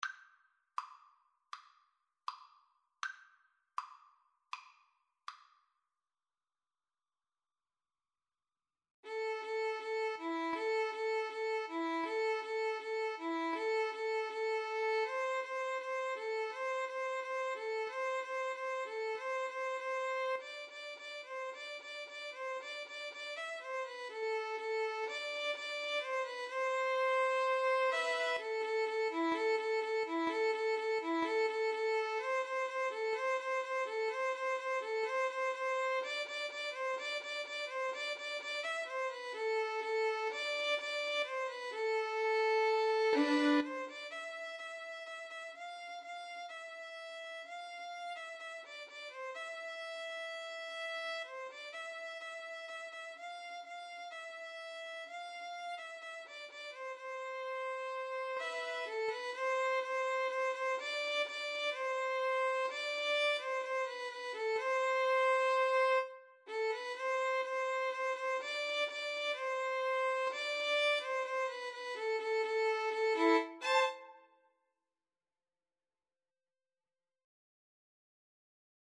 Free Sheet music for Violin Duet
A minor (Sounding Pitch) (View more A minor Music for Violin Duet )
Andante =c.80
Traditional (View more Traditional Violin Duet Music)
siman_tov_2VLN_kar2.mp3